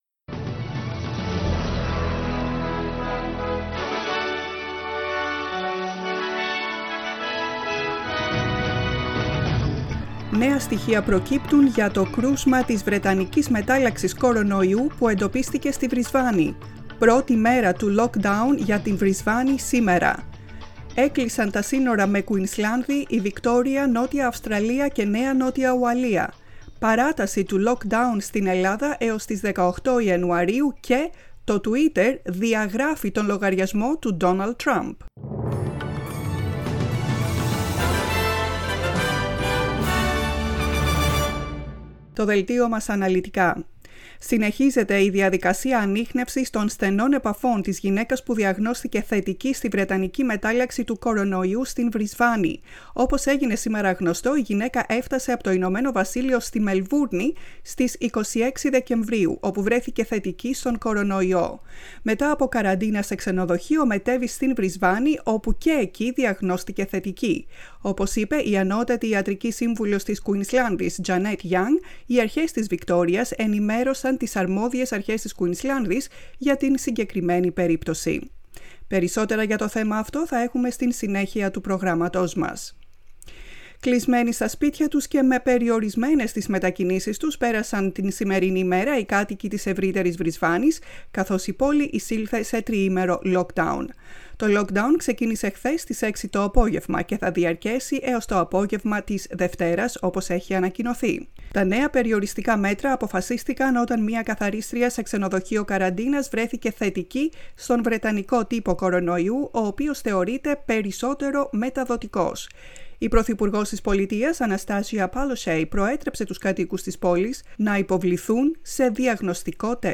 News in Greek, 9.01.21